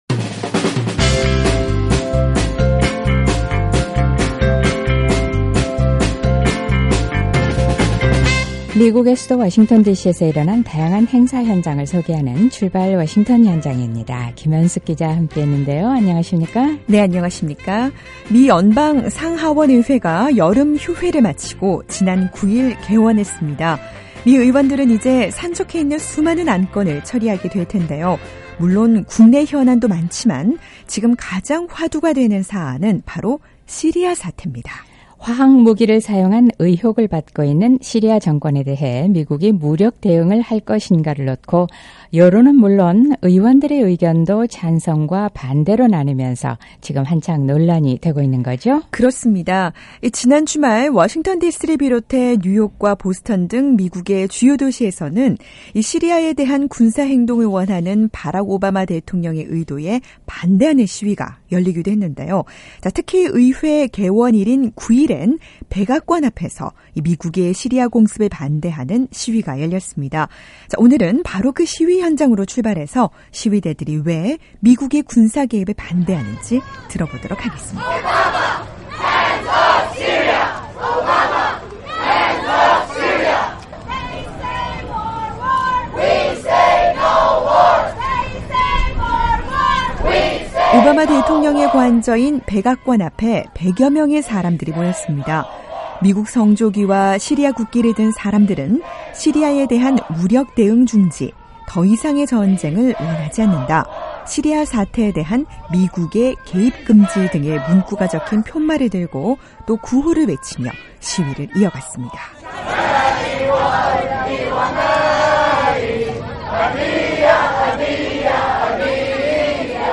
워싱턴 디씨에서도 백악관 앞에서 시리아 전쟁에 반대하는 시위가 열렸는데요, 왜 이들은 미국의 시리아 군사개입에 반대하는 걸까요? 오늘은 바로 그 시위 현장으로 출발해 봅니다.